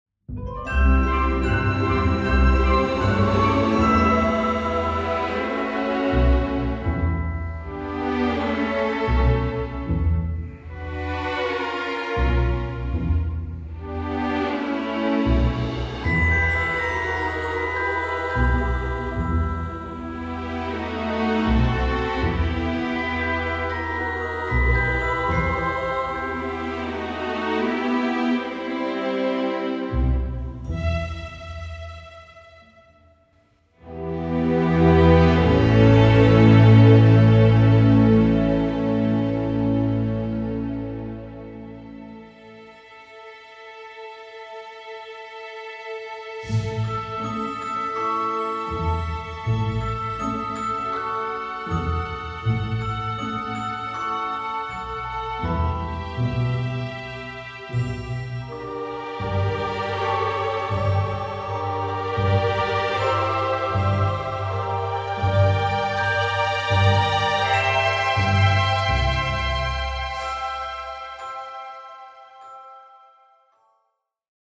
in the grand John Williams tradition